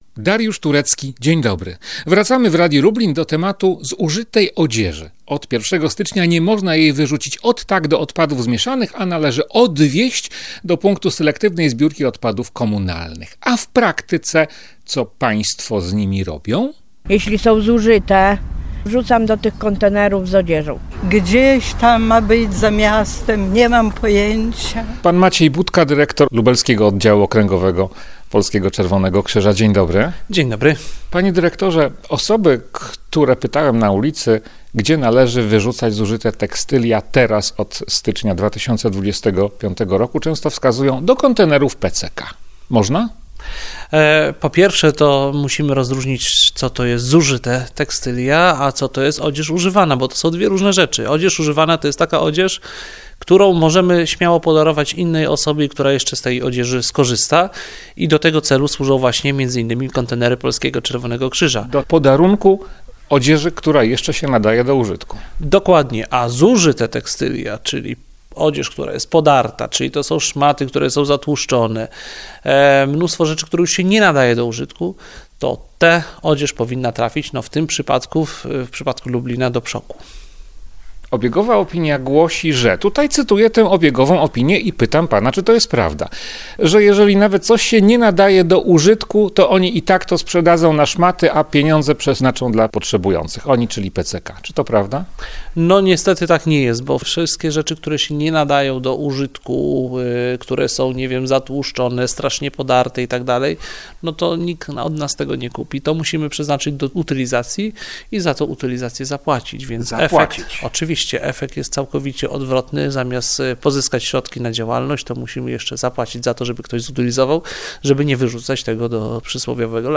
Dokąd wyrzucamy zużyte ubrania? „Do kontenerów PCK!” – odpowiadają niektórzy lublinianie, napotkani na ulicy.